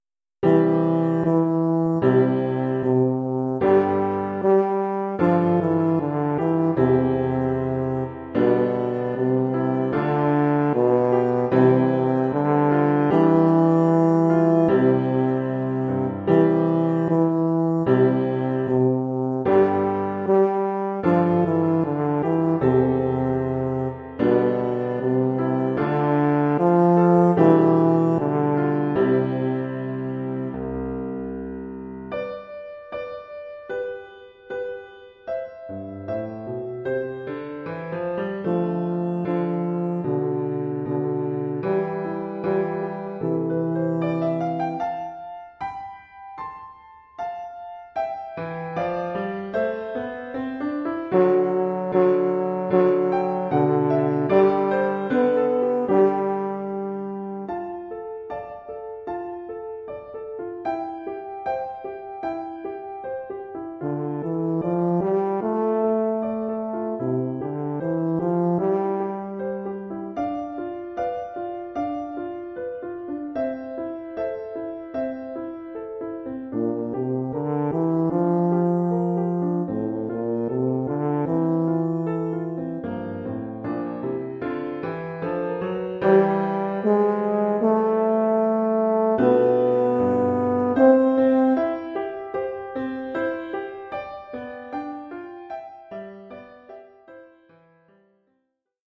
Oeuvre pour saxhorn basse et piano.
Oeuvre pour saxhorn basse / euphonium /
tuba et piano.
Niveau : débutant.